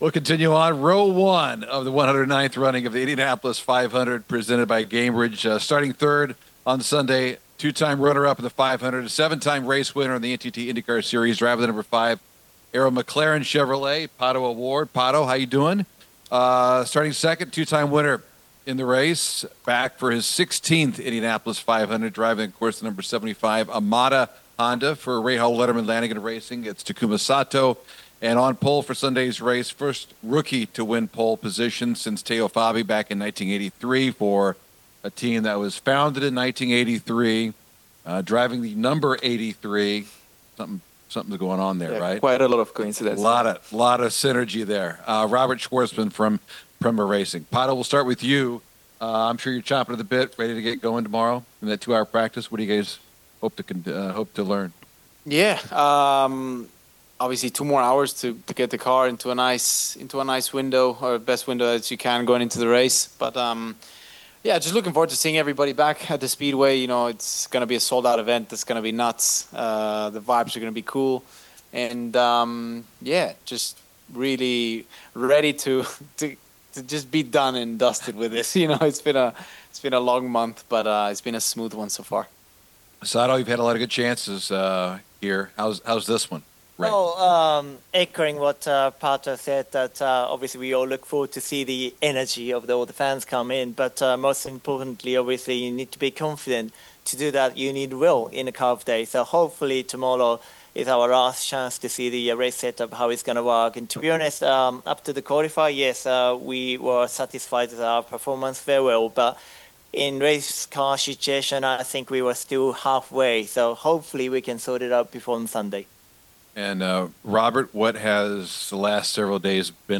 2025 Indianapolis 500 Row 1 Drivers Media Day Press Conference: Robert Shwartzman (Polesitter), Pato O’Ward and Takuma Sato